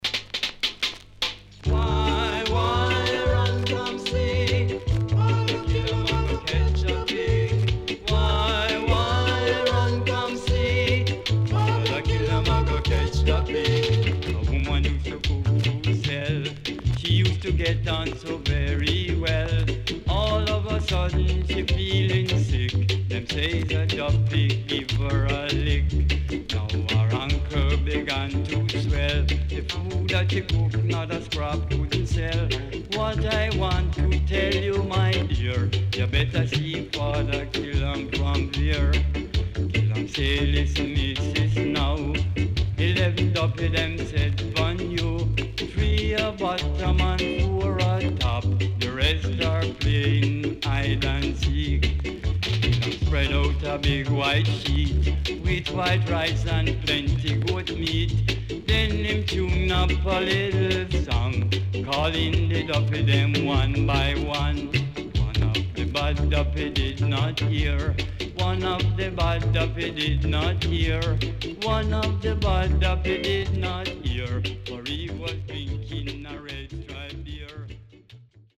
HOME > REGGAE / ROOTS  >  KILLER & DEEP  >  INST 70's
Killer Sax Inst
SIDE A:全体的にチリノイズがあり、所々プチパチノイズ入ります。